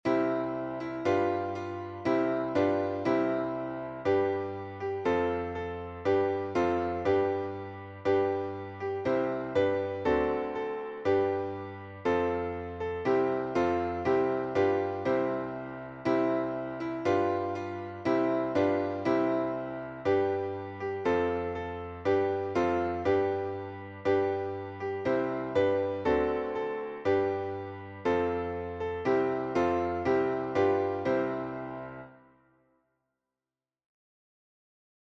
The new cadets, ignorant of the words and tune, could only listen, but a feeling of friendliness and comfort came over them at the sound of the simple tune.